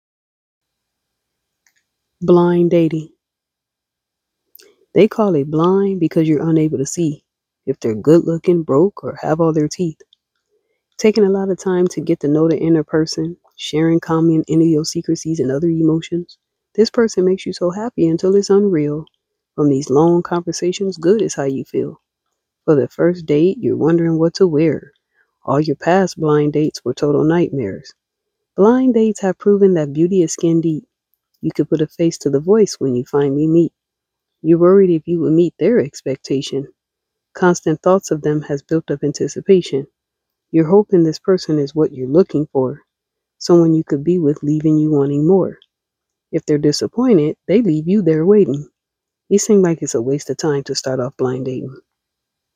You read very well!